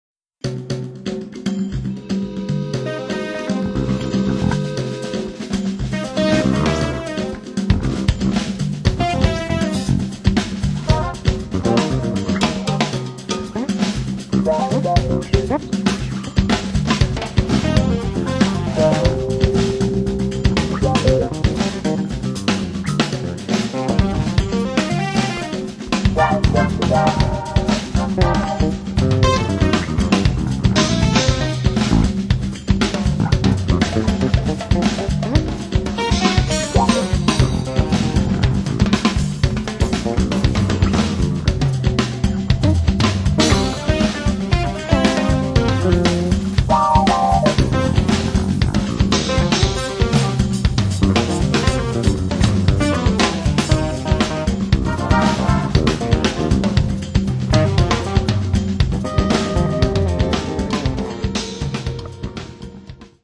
progr. Keyb. Perc. Bass/Guitar
Sax Soprano
Vocal
Guitar
Keyboards - el. piano
Percussion